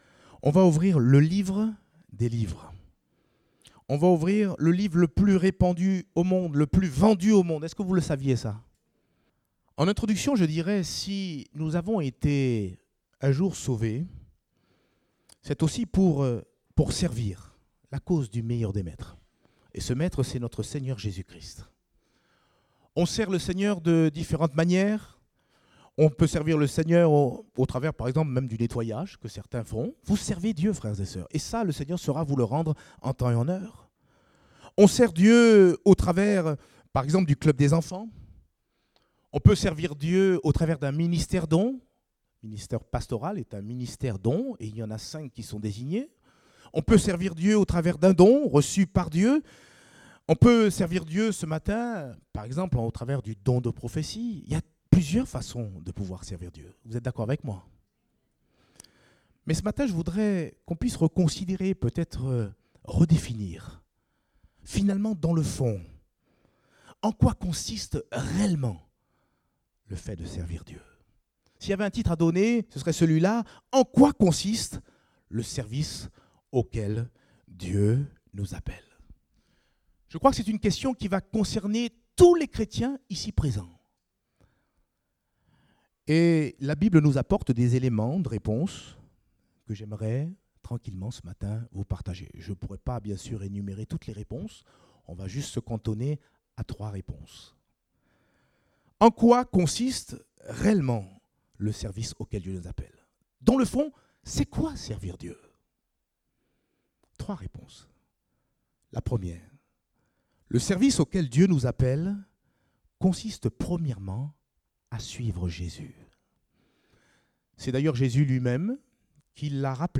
Date : 8 octobre 2017 (Culte Dominical)